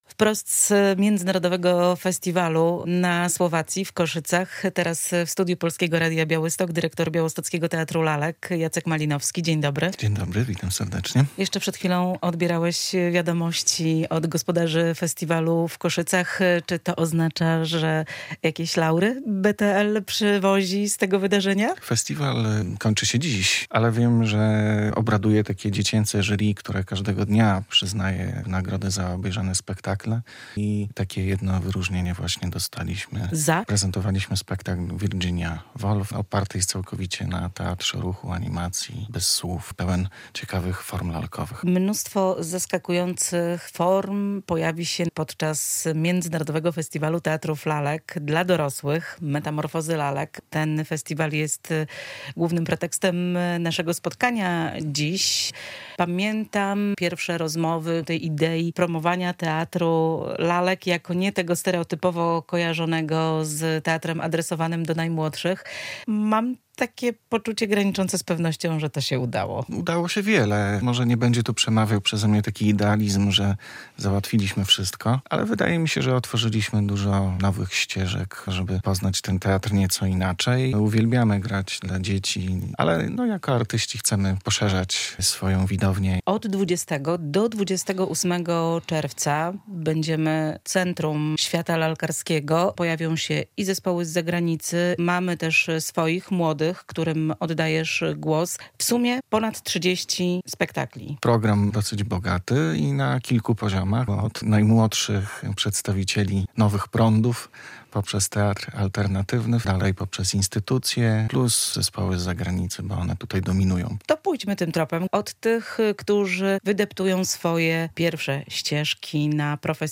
O festiwalu rozmawia